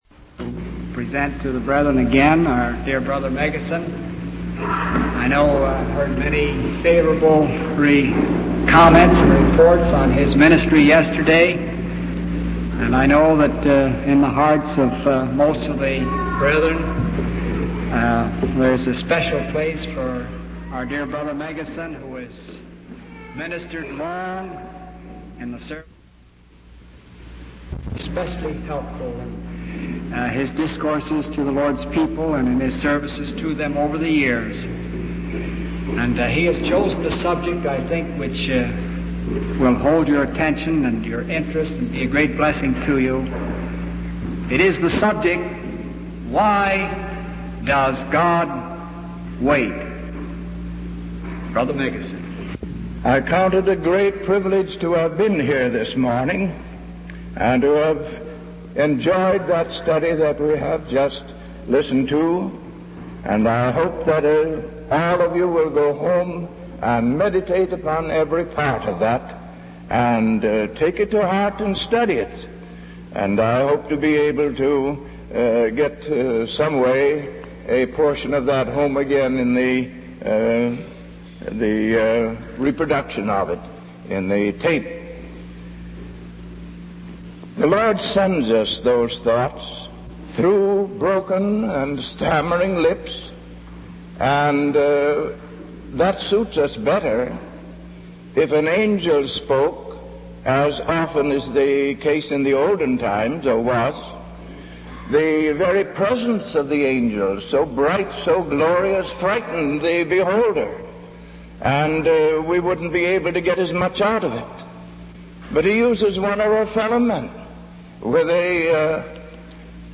From Type: "Discourse"
Given in New Brunswick, NJ in 1961